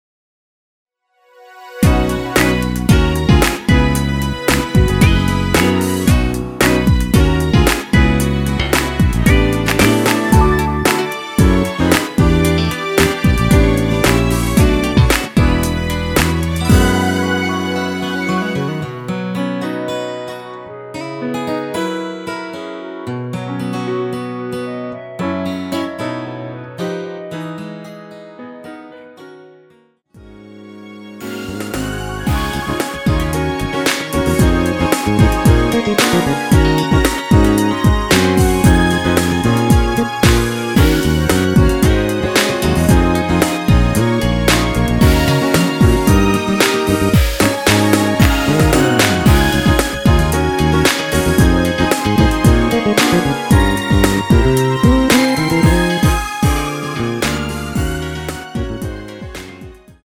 원키에서(+3)올린 멜로디 포함된 MR 입니다.(미리듣기 참조)
앞부분30초, 뒷부분30초씩 편집해서 올려 드리고 있습니다.
중간에 음이 끈어지고 다시 나오는 이유는